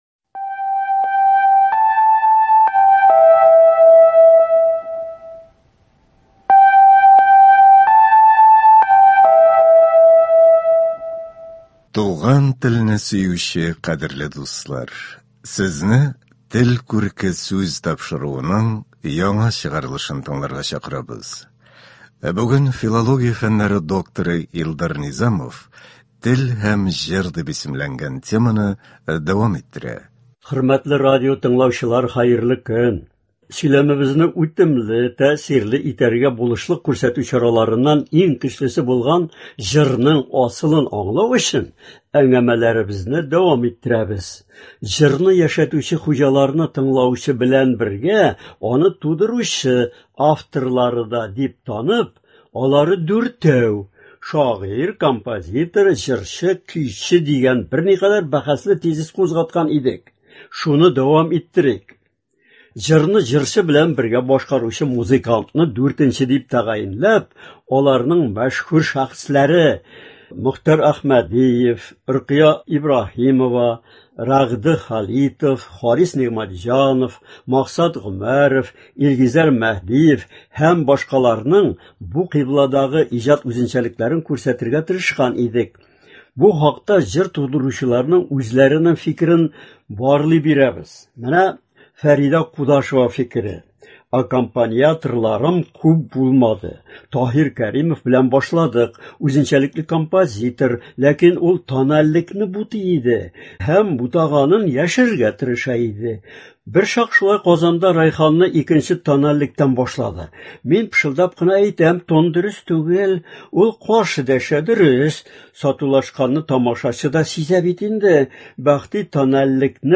Өченче сәхифәдә Адлер Тимергалинның “Миллият сүзлеге” дигән хезмәтеннән “Ай тәңресе” дигән мәкалә укыла.